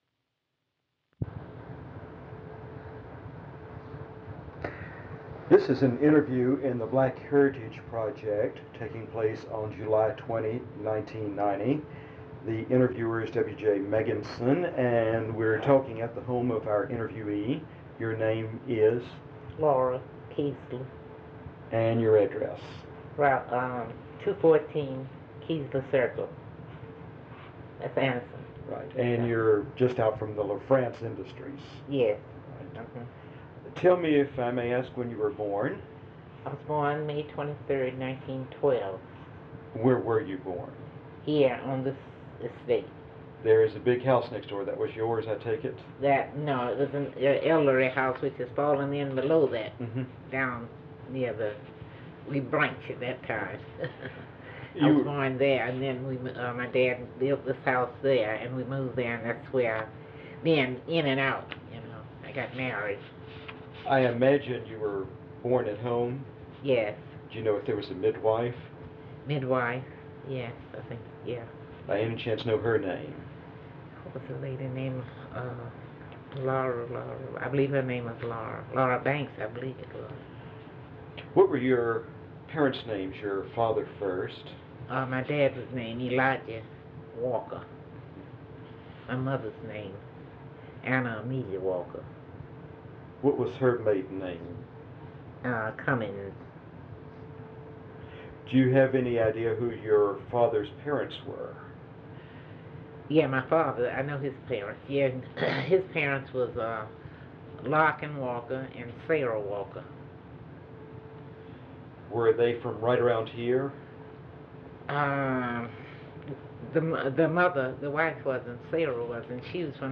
Part of Interview